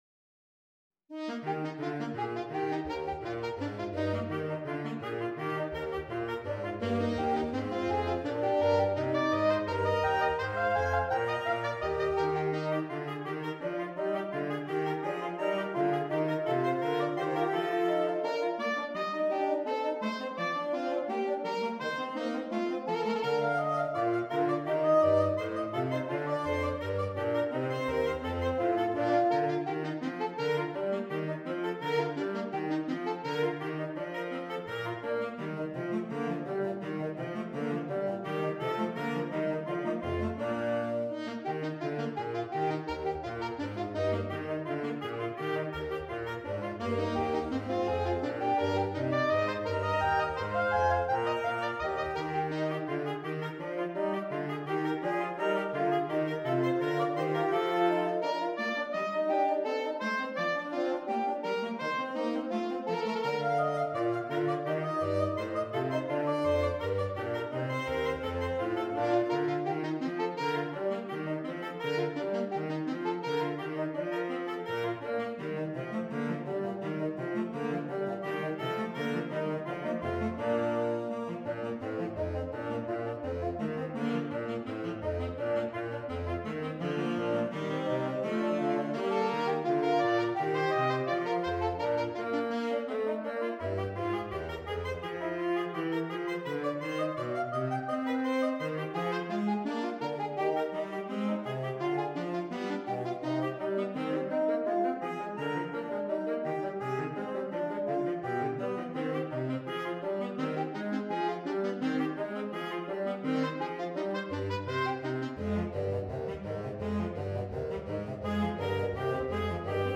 Voicing: Saxophone Quartet (SATB)